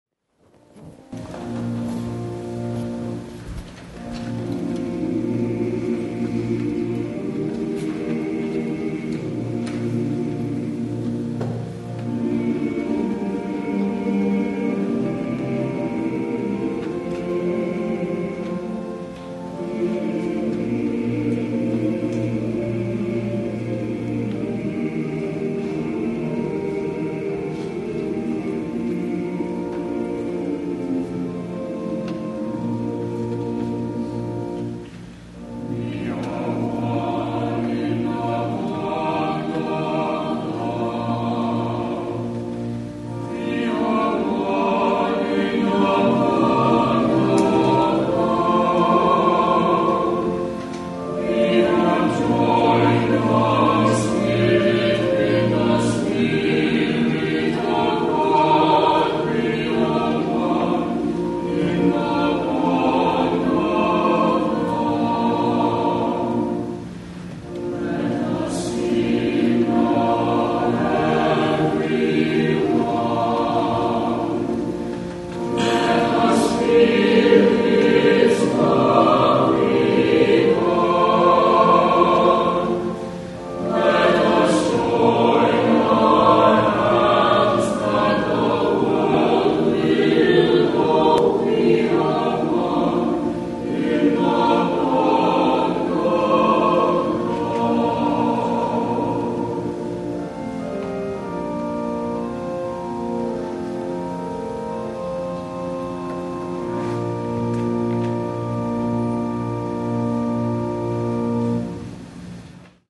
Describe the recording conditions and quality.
Morningside Presbyterian Church, Atlanta